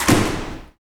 SI2 DOORS05R.wav